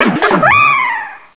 pokeemerald / sound / direct_sound_samples / cries / darumaka.aif
darumaka.aif